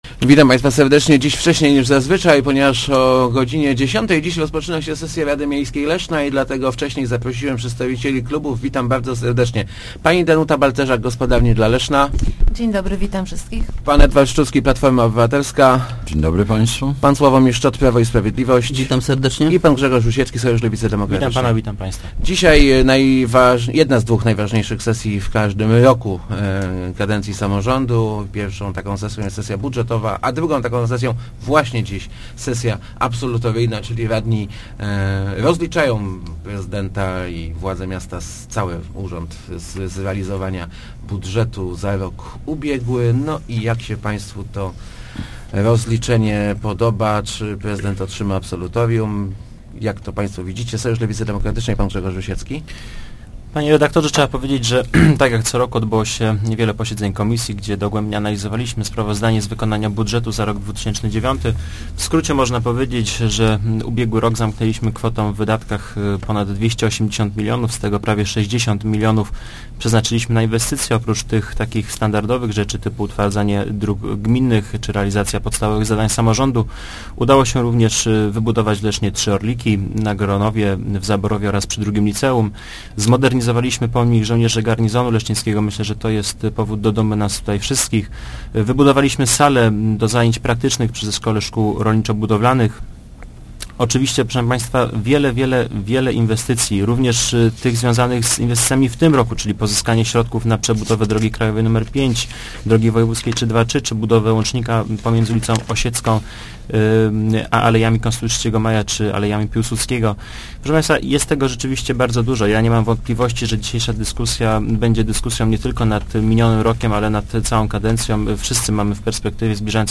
SLD i Gospodarni dla Leszna s� za udzieleniem absolutorium prezydentowi Leszna za ubieg�y rok. Mimo nie�atwej sytuacji gospodarczej uda�o si� zrealizowa� wi�kszo�� inwestycji - mówi� Rozmowach Elki Grzegorz Rusiecki z Lewicy; wtórowa�a mu Danuta Balcerzak z GdL.